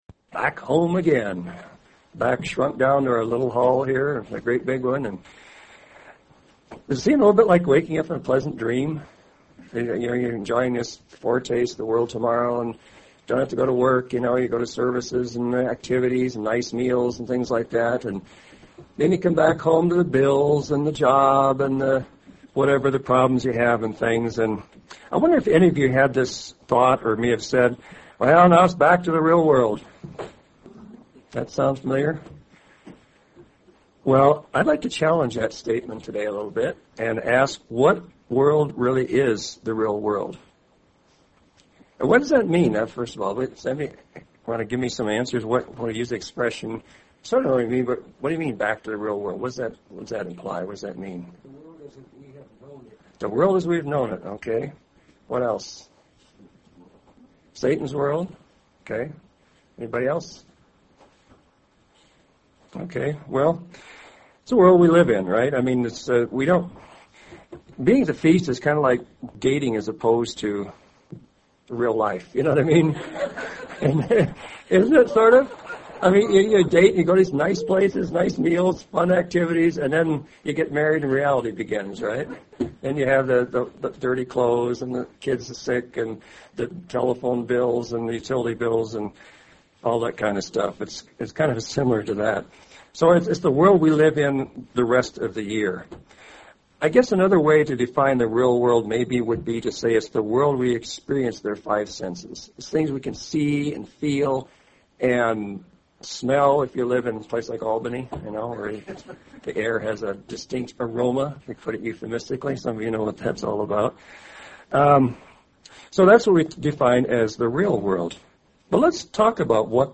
Given in Central Oregon
UCG Sermon Studying the bible?